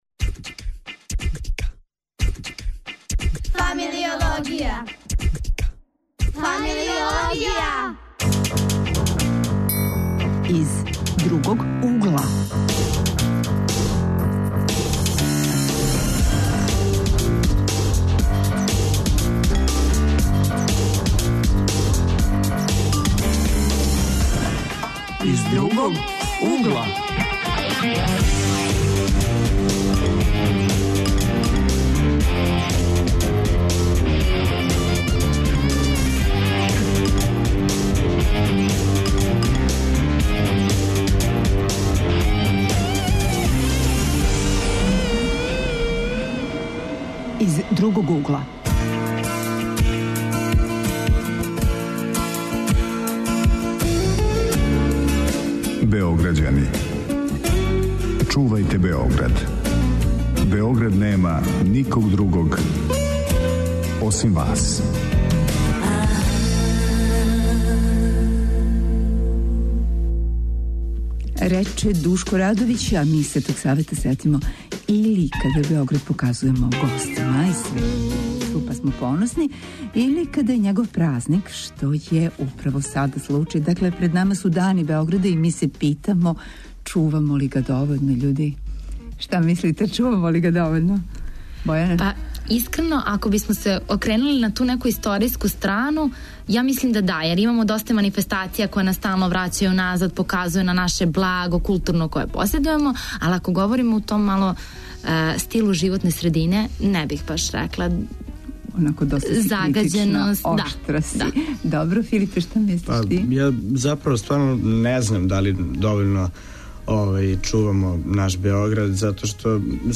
Тема: Београд и млади - у сусрет Данима Београда, гости - средњошколци.